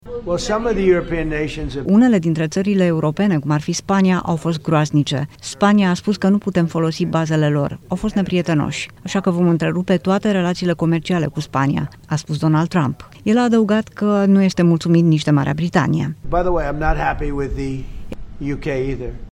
Ieri, în cadrul unei întâlniri cu cancelarul german Friedrich Merz la Casa Albă, Donald Trump a amenințat că va impune un embargo comercial total asupra Spaniei.